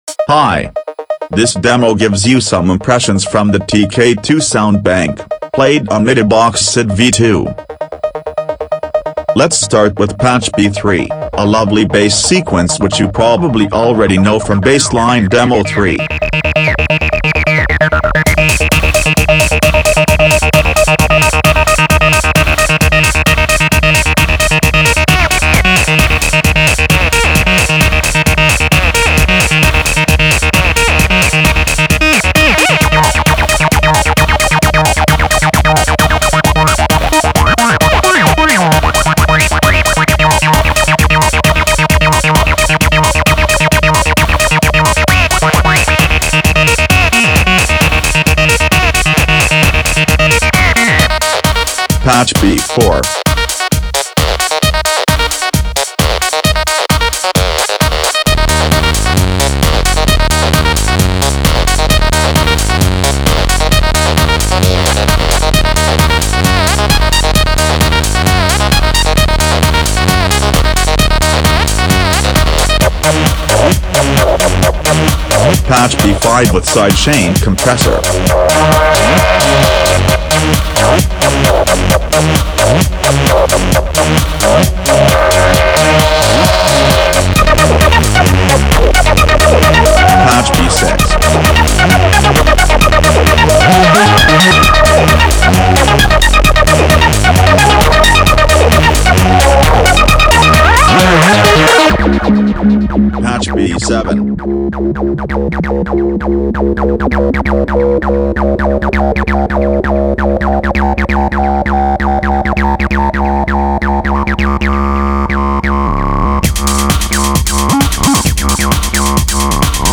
mbsid_v2_tk2_soundbank_demo.mp3